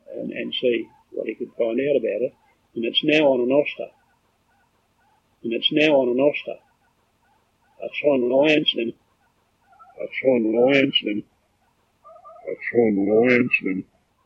AUDIO ENHANCED